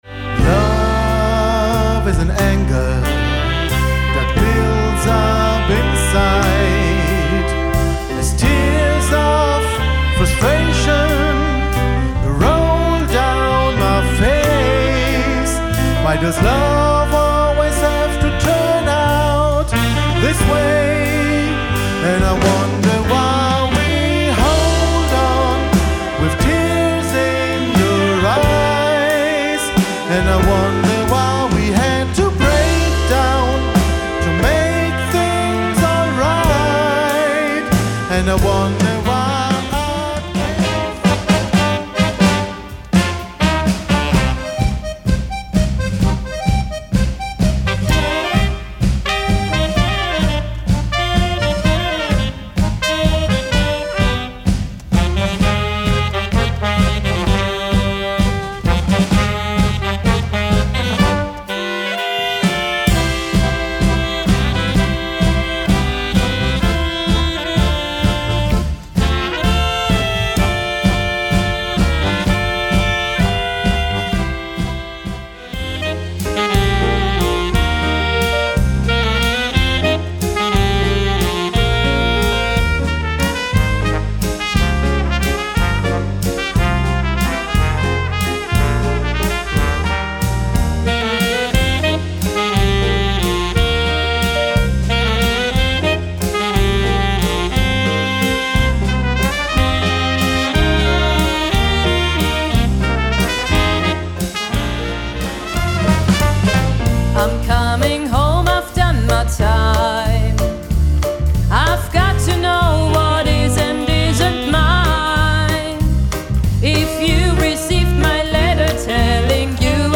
Live - Mitschnitte (Auszüge)
Tänze - Standard
1. langsamer Walzer, 2. Tango, 3. Wiener Walzer,
4. Foxtrott, 5. Slowfox, 6. Quickstep